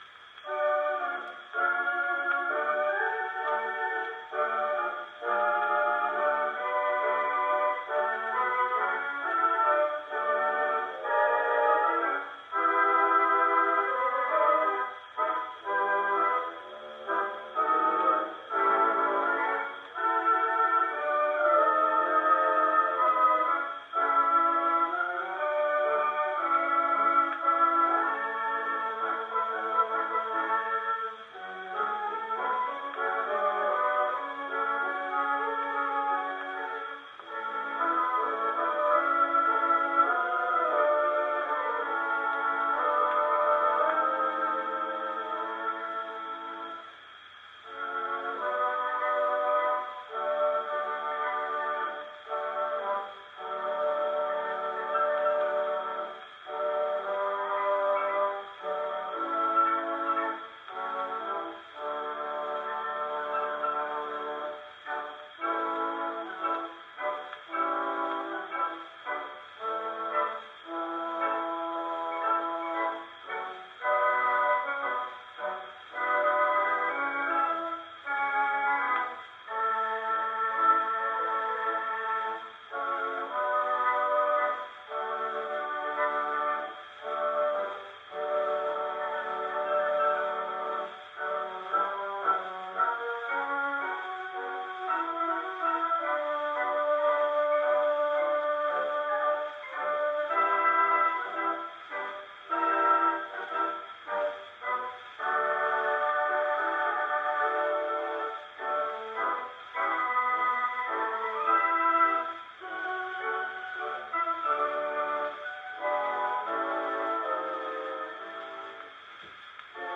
Played on the Wurlitzer Organ) Pipe Organ Solo